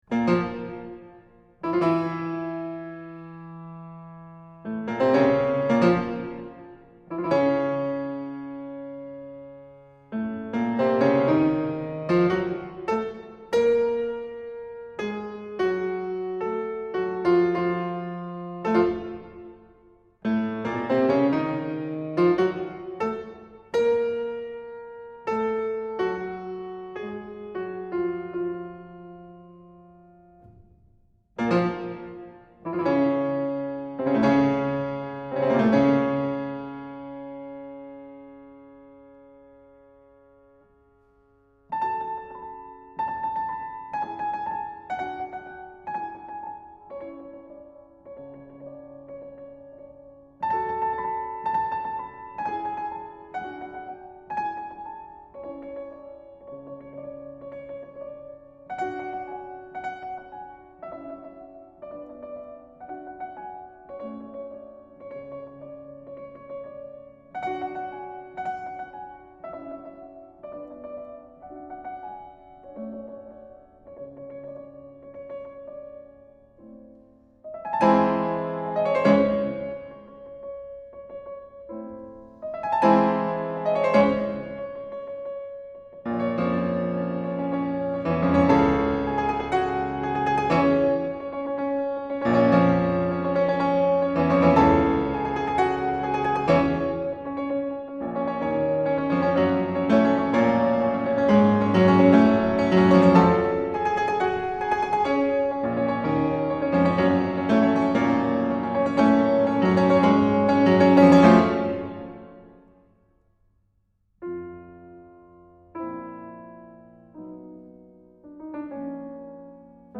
And if you're REALLY ambitious, here's the piece in its entirety, performed on the piano (pianist: Olli Mustonen):